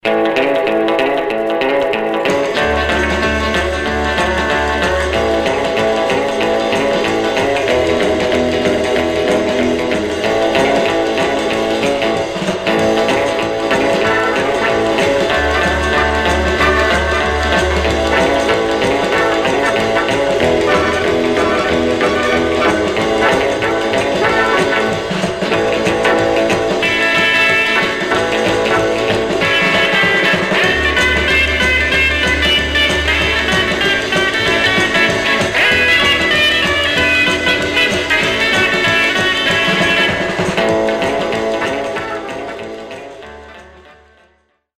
Some surface noise/wear
Mono
R & R Instrumental